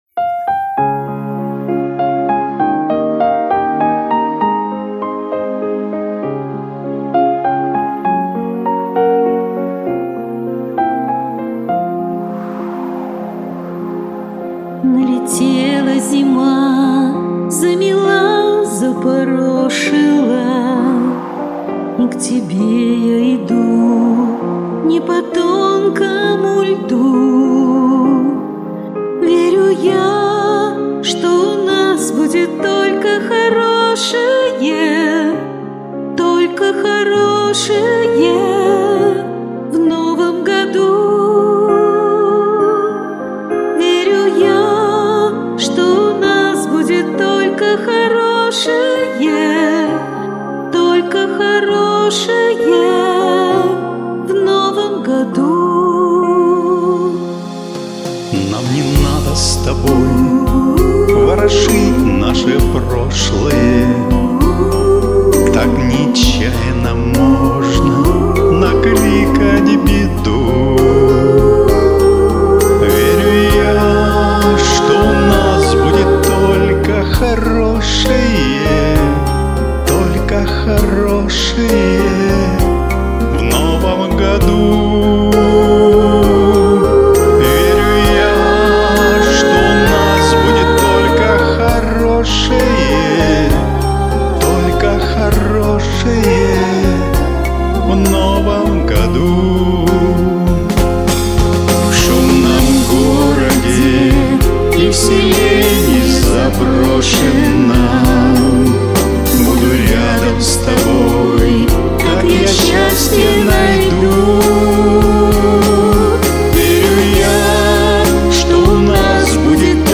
Чувственный дуэт сложился!!!! applodd9